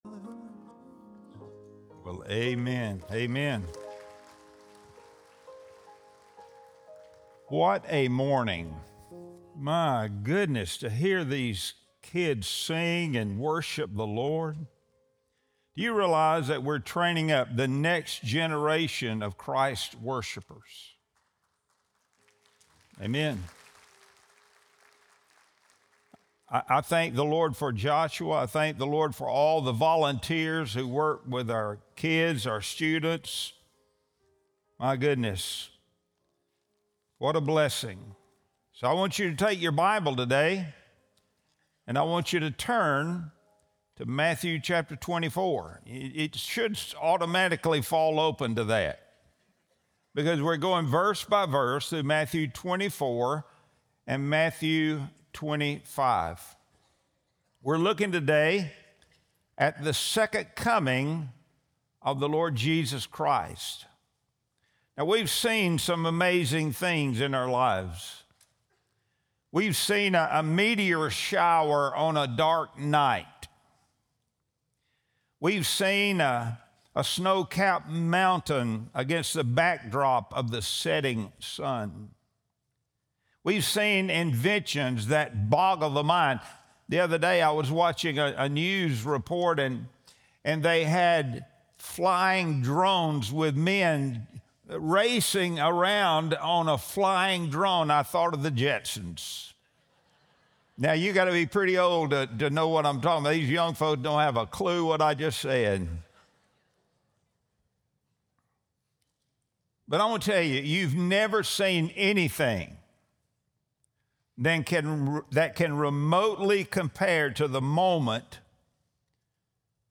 Sunday Sermon | October 26, 2025